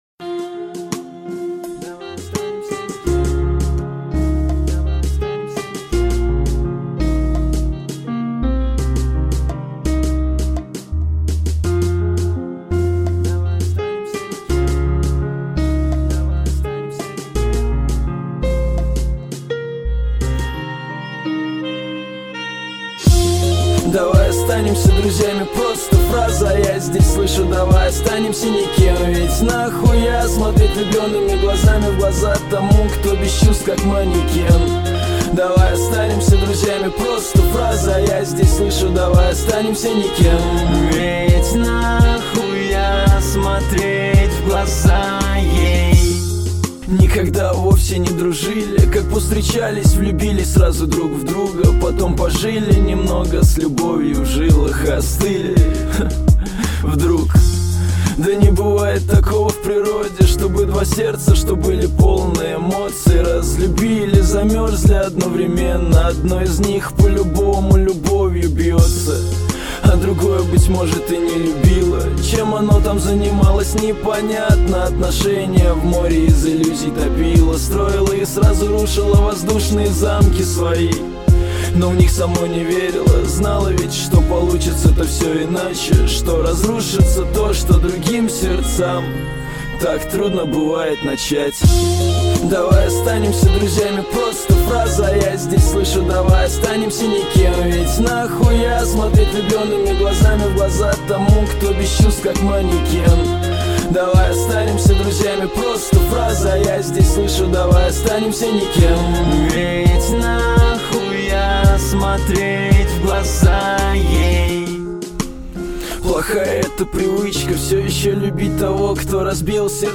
Данная песня находится в музыкальном жанре Русский рэп.
Русский рэп